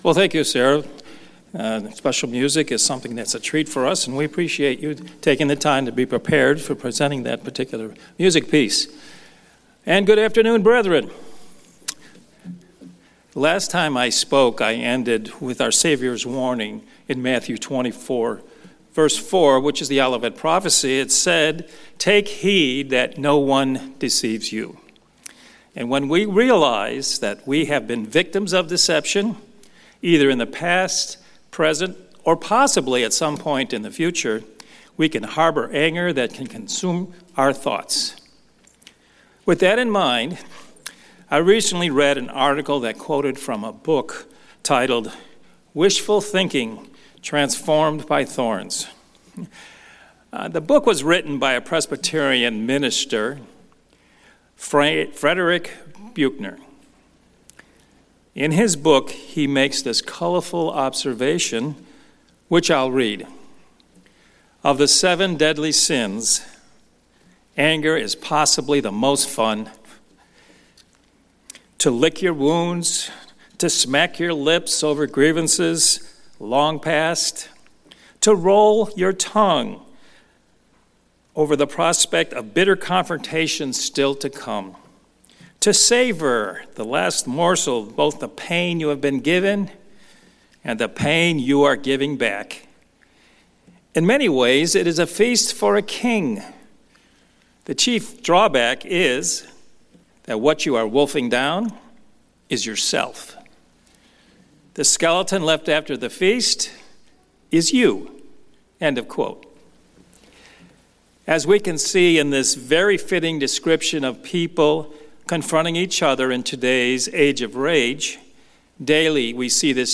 This sermon discusses the hidden anger that can linger for years and how to identify and remove it.
Given in Houston, TX